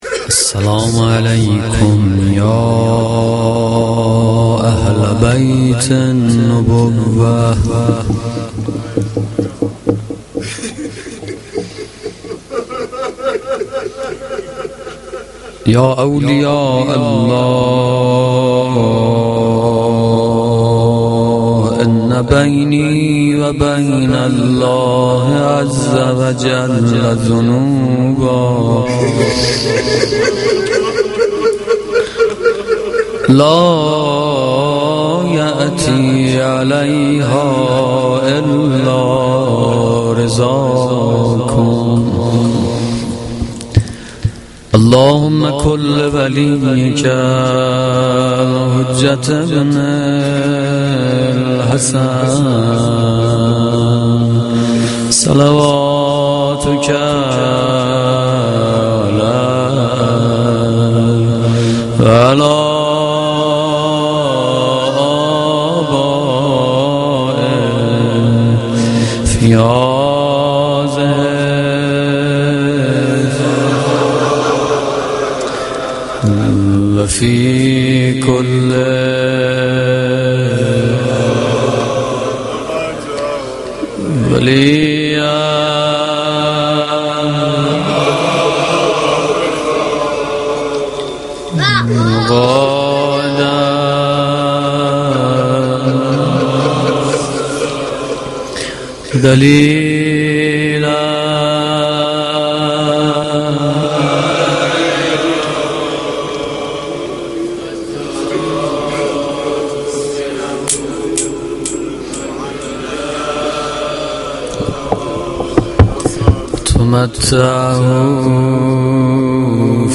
مداحی روضه شب پنجم